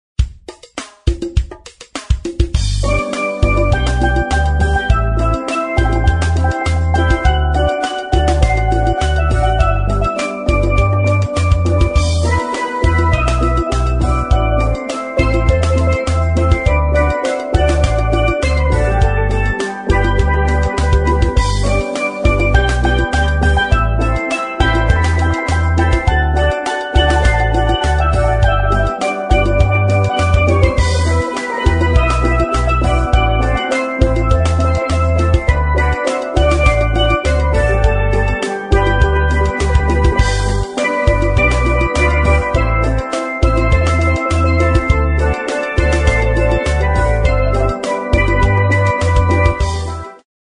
• Authentic Caribbean steel pan band